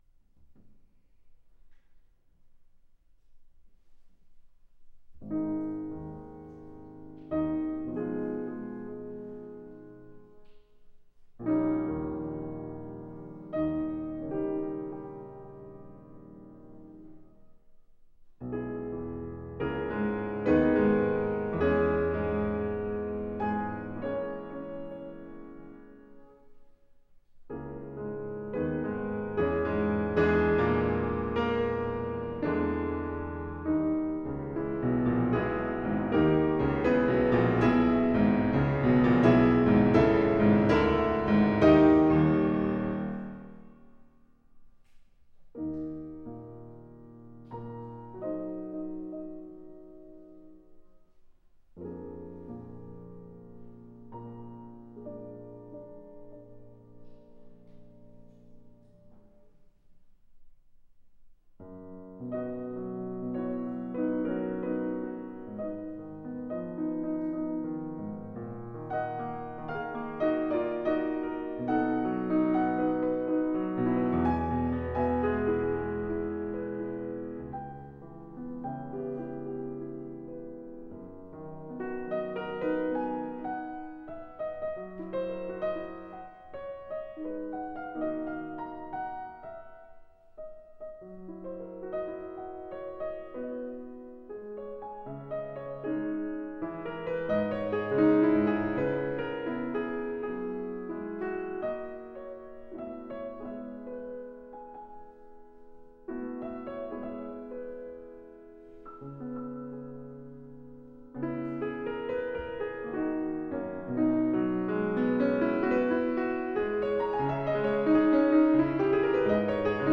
AUDIO SOLO RECITAL LIVE 8.15.10 Kumho Art hall, Seoul, KOREA SCRIABIN 1.